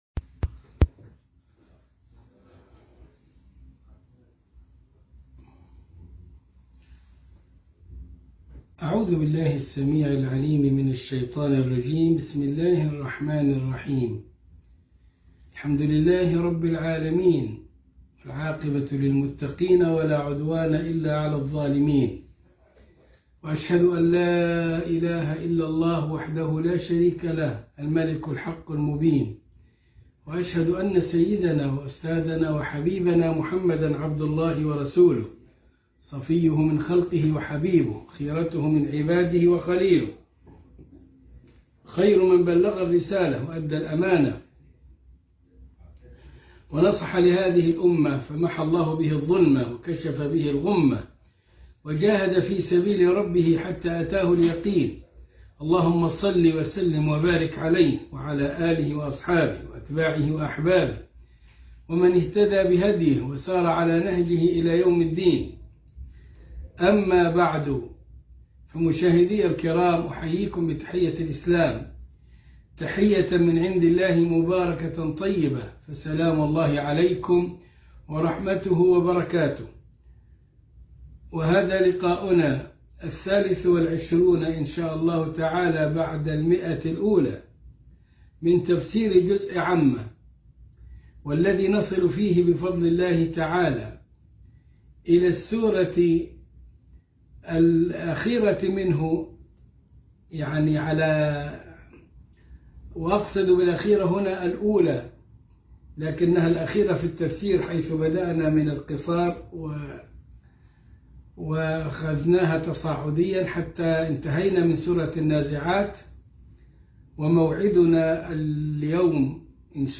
الدرس ١٢٣ من الإبريز في تفسير الكتاب العزيز سورة النبأ